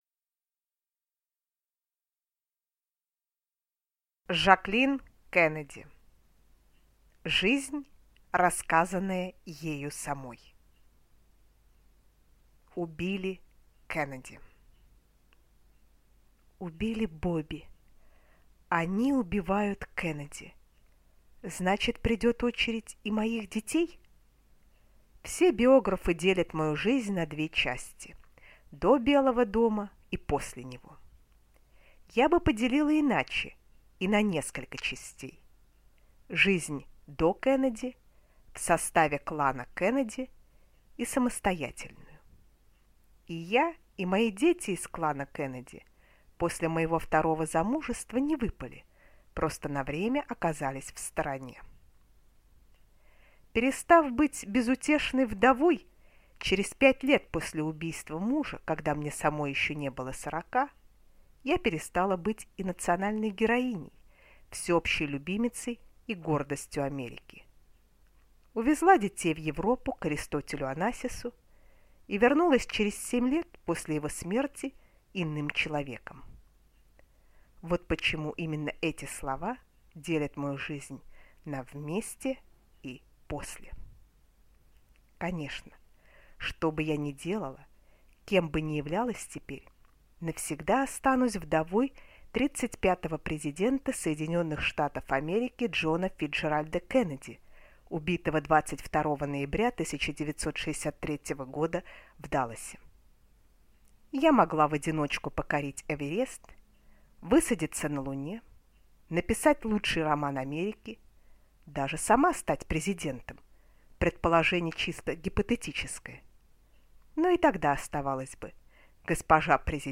Аудиокнига Жаклин Кеннеди. Жизнь, рассказанная ею самой | Библиотека аудиокниг